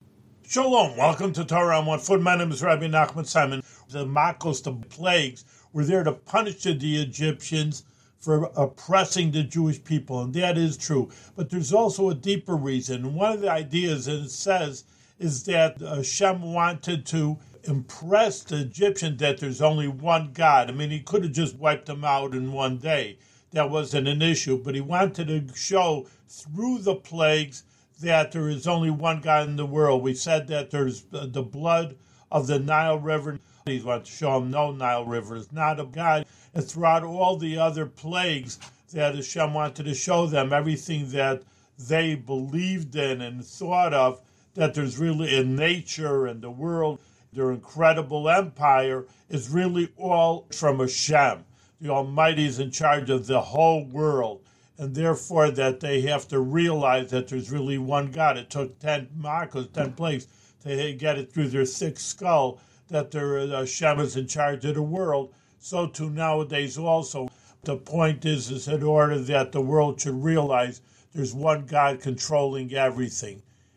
One-minute audio lessons on special points from weekly Torah readings in the Book of Exodus.